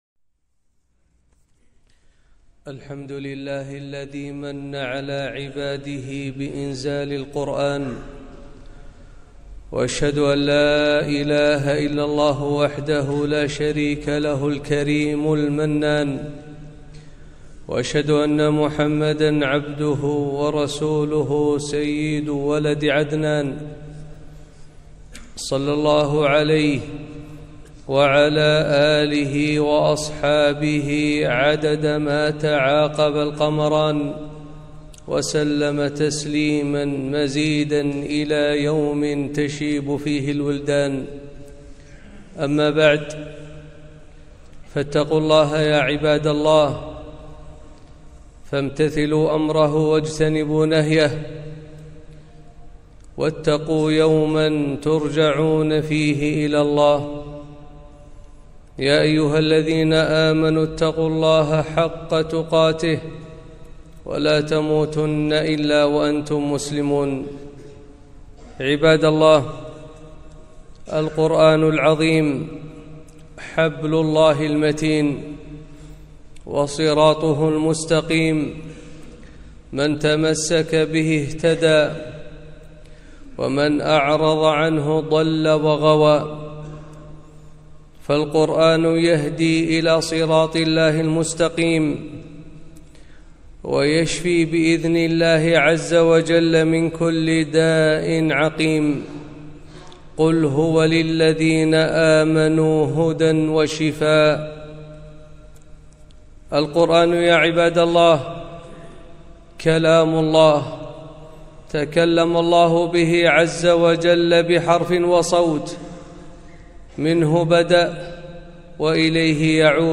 خطبة - القرآن فضائل وأحكام - دروس الكويت